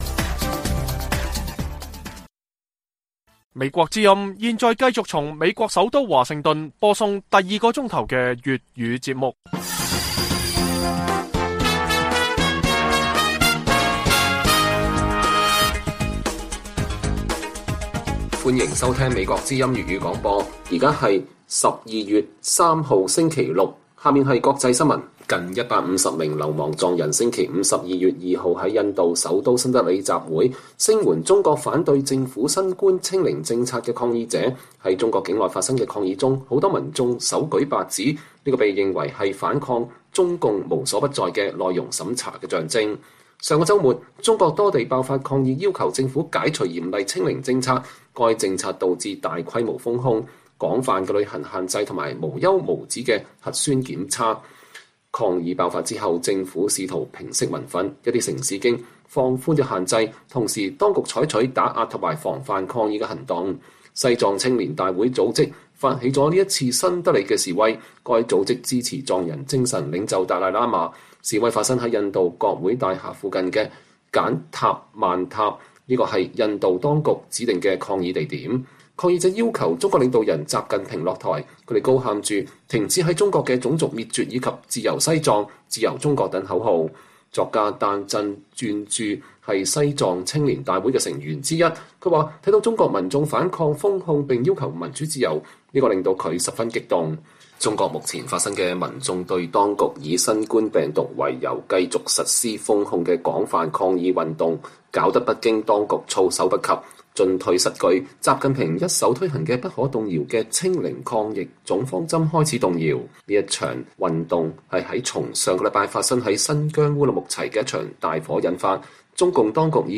粵語新聞 晚上10-11點 : “白紙運動”背後是美國的5億經費？